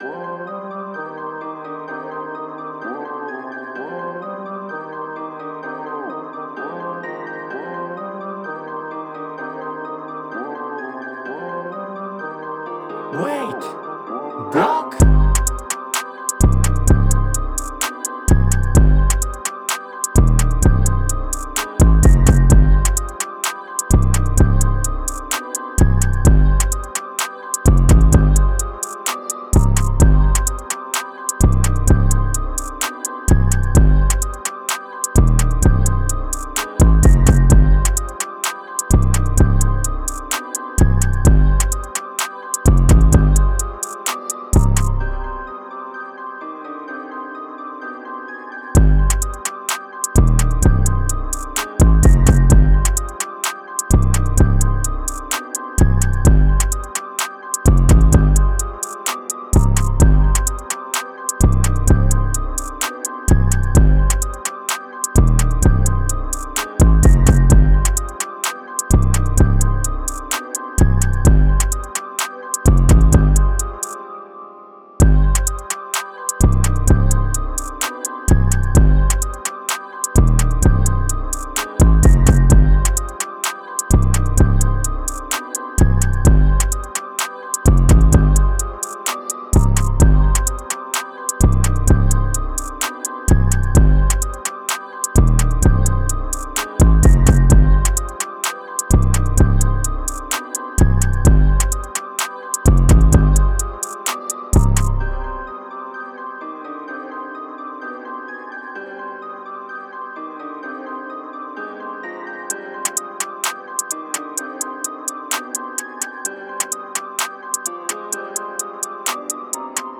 Time – (3:10)　bpm.130